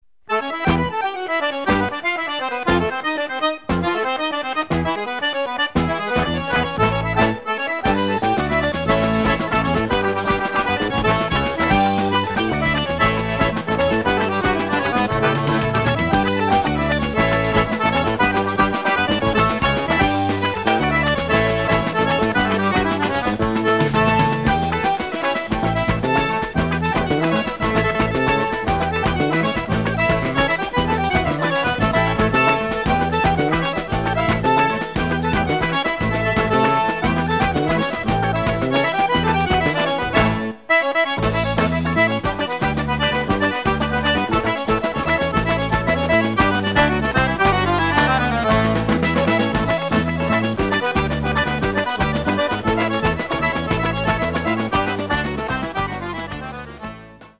A Description of a Piece of Music I have Listened to I have listened to a Brazilian Samba and this piece of music has a lot of Rhythm which makes you want to dan
Forro_na_Penha.mp3